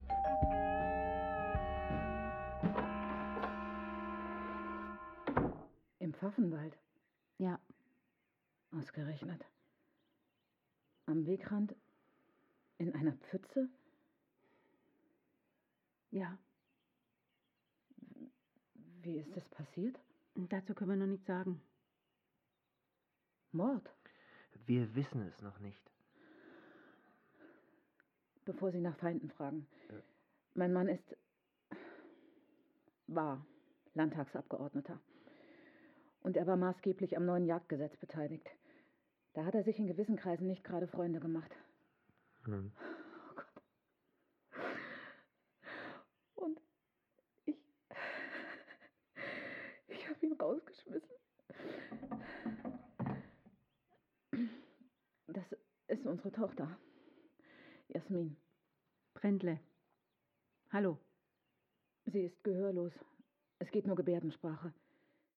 Die sogenannte Altersschwerhörigkeit hat zur Folge, dass vor allem die höheren Klangwellen schlechter gehört werden und das Hörerlebnis insgesamt dumpfer wird.
Hören im Alter von 60 Jahren: